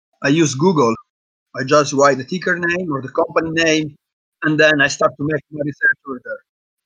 Bad Microphone
Generally, computer built-in microphones are of low quality to begin with.
This makes them more susceptible to pick up noise and echo.
Here’s what a bad microphone sounds like.
bad-mic.mp3